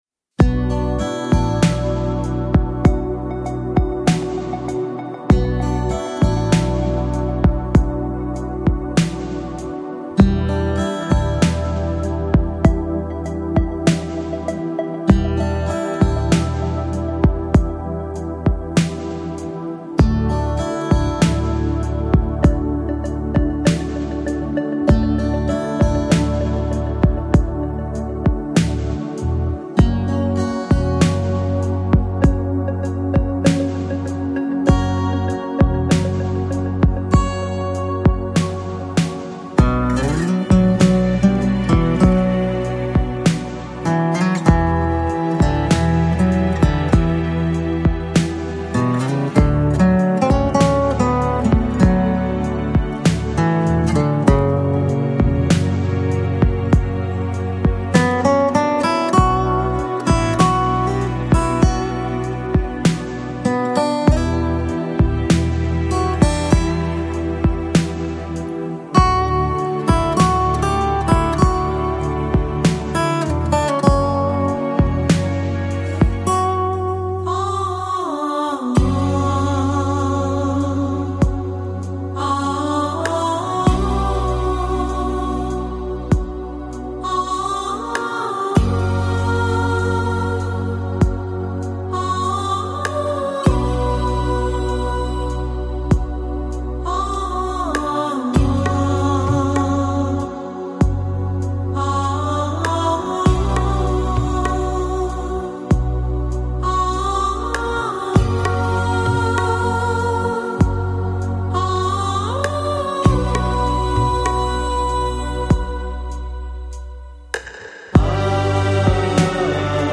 New Age, World Music